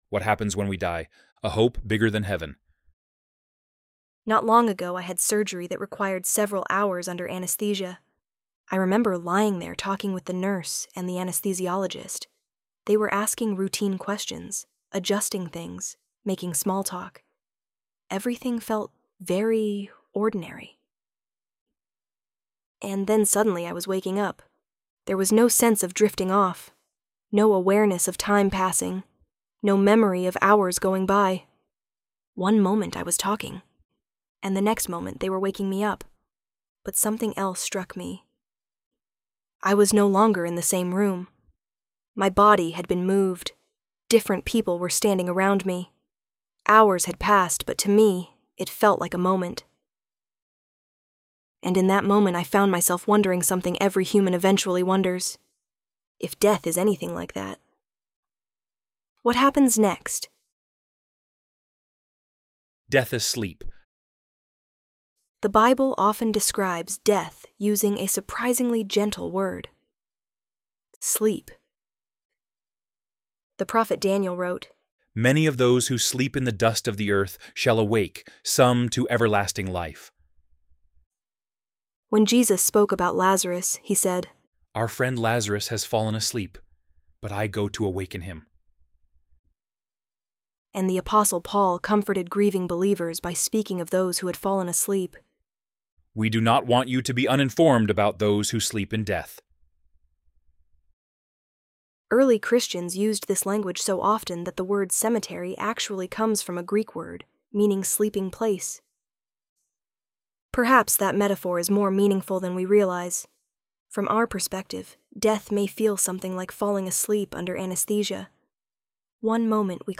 ElevenLabs_What_Happens_When_We_Die_.mp3